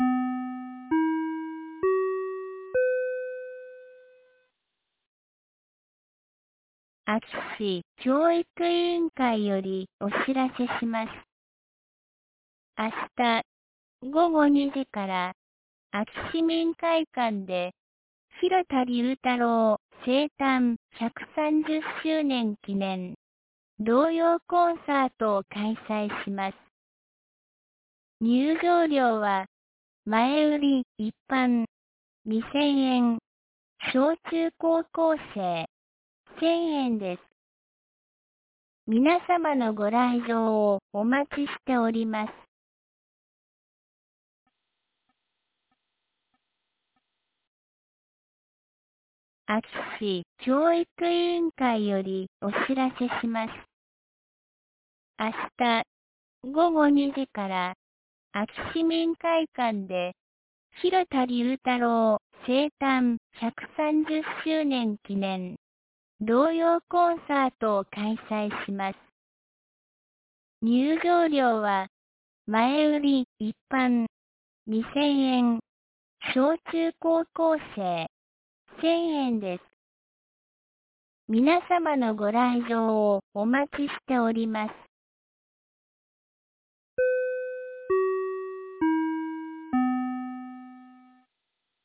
2022年10月09日 10時31分に、安芸市より全地区へ放送がありました。